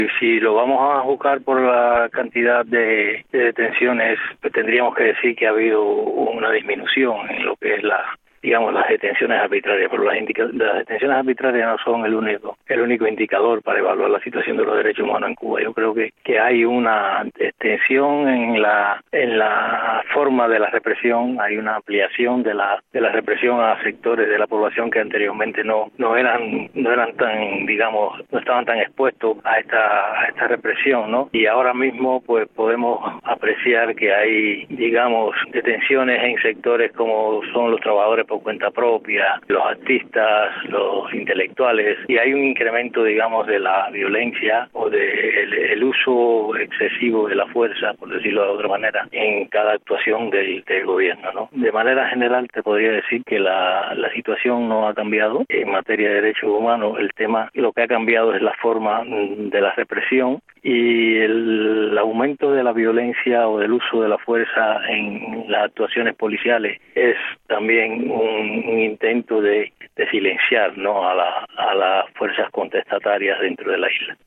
(Con entrevistas concedidas a Radio Martí)